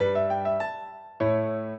piano
minuet14-11.wav